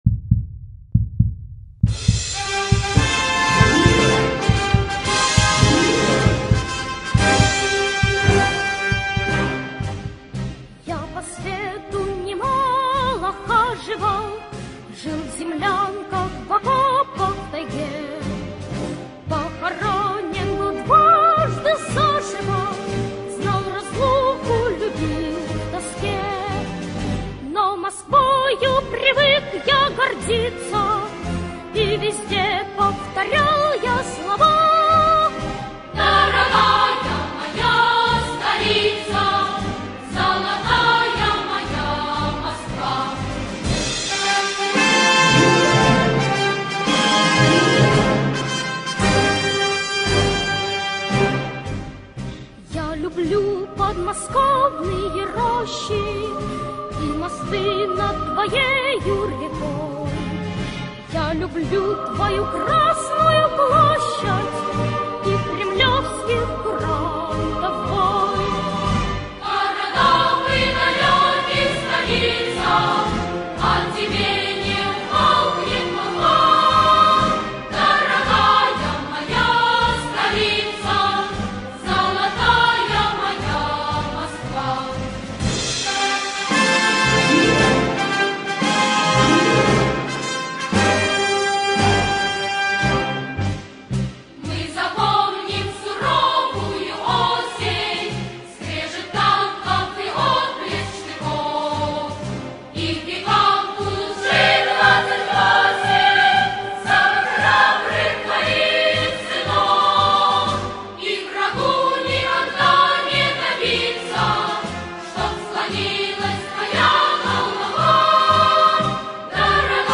советская песня о Москве военных лет (детский хор